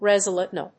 resiliently.mp3